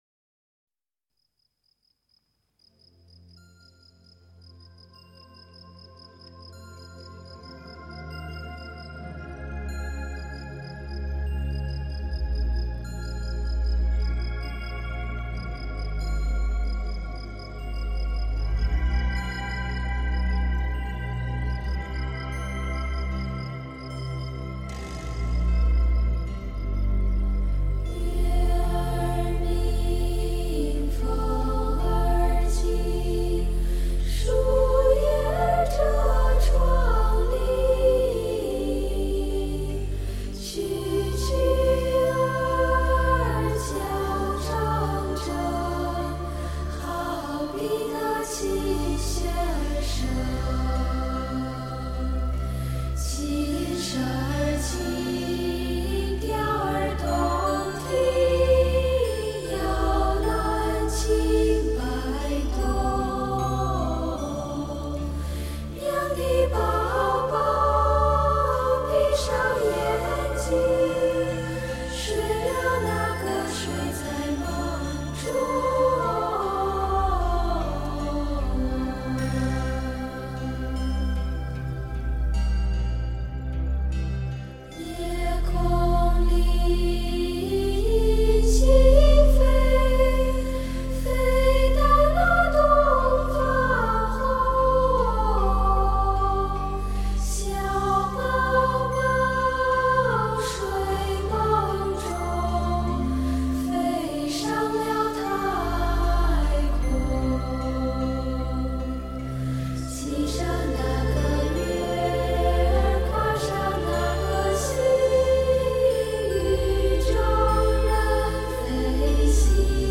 唱出摇篮曲特有的温柔情怀，风格清新，宛如天籁，给予心灵一种深层的感动。
其中的主要团员均为一时之选，演唱技巧纯熟、情感表达细腻，歌声恬净宛如天籁，深受各方喜爱。